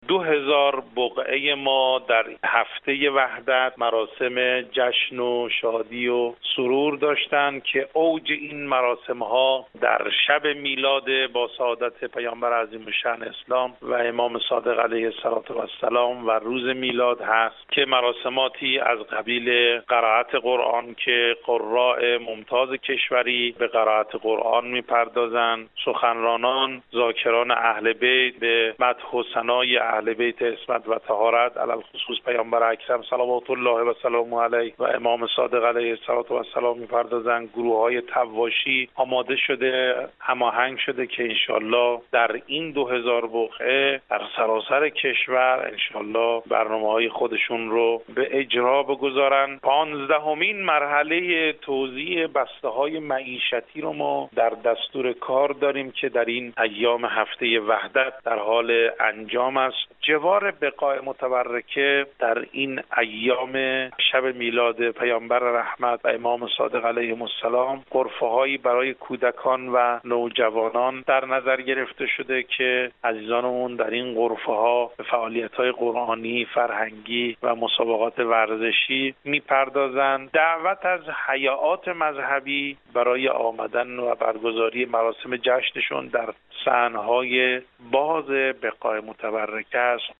به گزارش رادیو زیارت، حجت الاسلام محمد نوروزپور مدیرکل بقاع متبرکه و اماکن مذهبی سازمان اوقاف و امور خیریه کشور در گفتگو با خبر رادیو اعلام کرد : همزمان با هفته وحدت در دو هزار بقعه متبرکه مراسم جشن وسرور برپا شده است که اوح آن امشب و فردا در این آستان های مقدس برگزار خواهد شد .